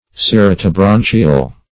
Search Result for " ceratobranchial" : The Collaborative International Dictionary of English v.0.48: Ceratobranchial \Cer`a*to*bran"chi*al\, a. (Anat.)